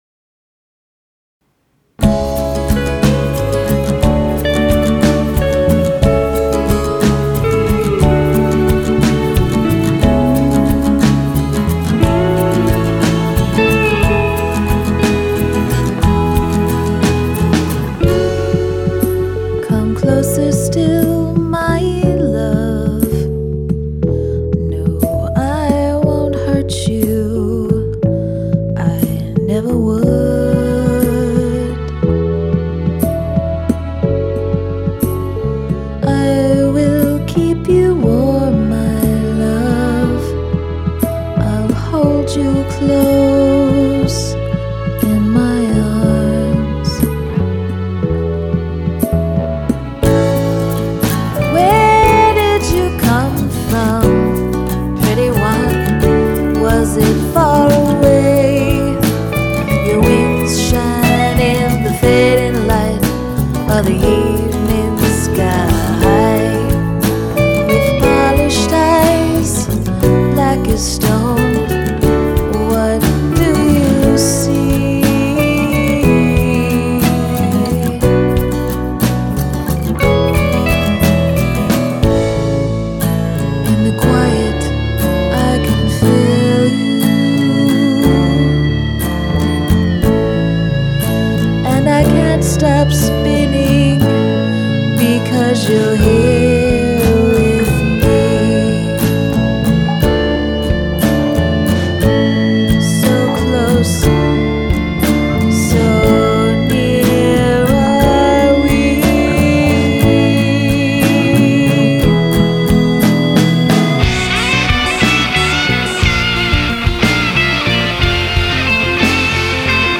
Composition must be Through Composed
The Guitar solos!
There’s some mix issues I have as well.
I really wanted to hear more bass and drums.